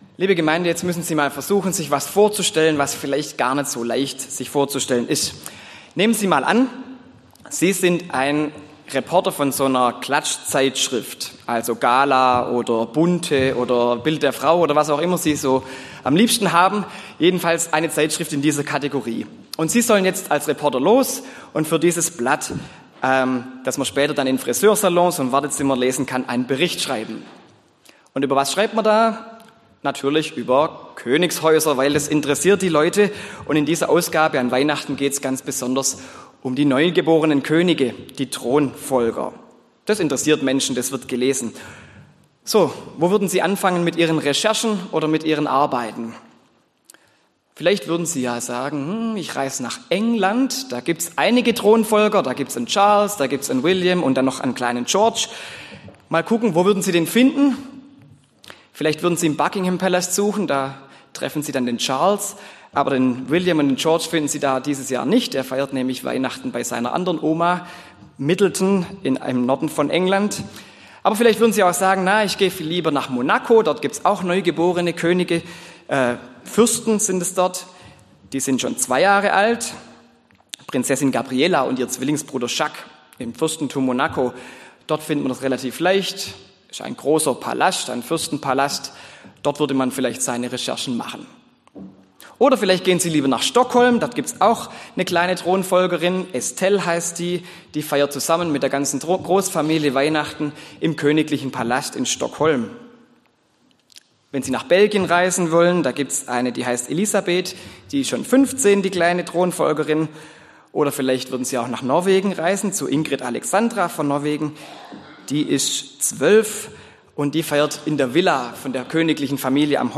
Predigt an Weihnachten: Ins Unscheinbare wird der König geboren (Mt 2,1-12)